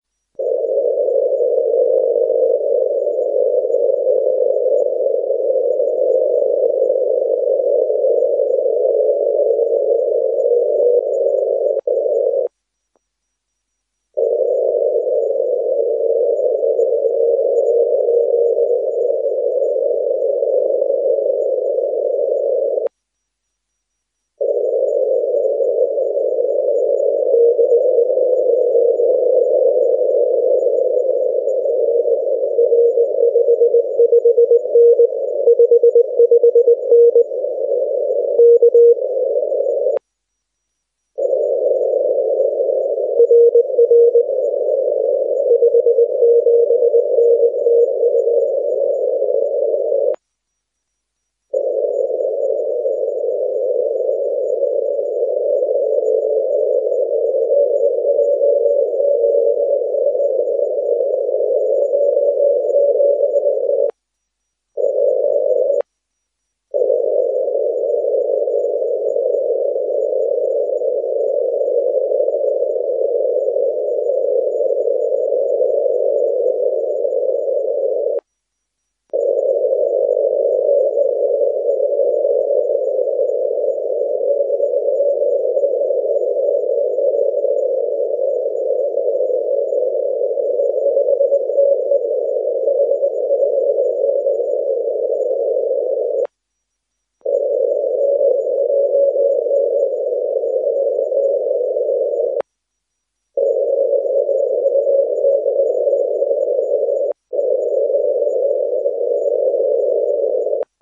First F on this season. Variation of CW tone is caused by my RX-VFO manipulation for clarity.